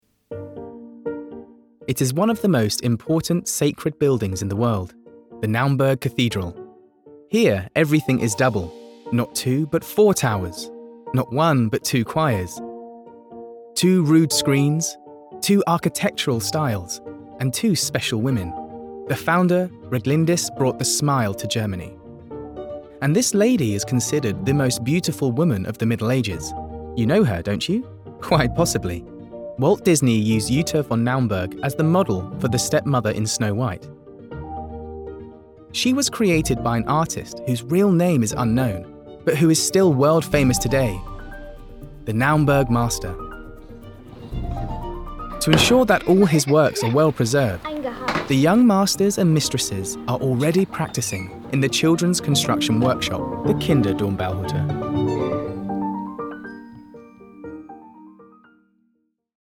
Inglés (Británico)
Comercial, Joven, Natural, Travieso, Amable
Audioguía